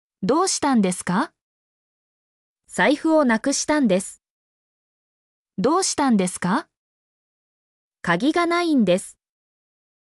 mp3-output-ttsfreedotcom-6_i08to2YP.mp3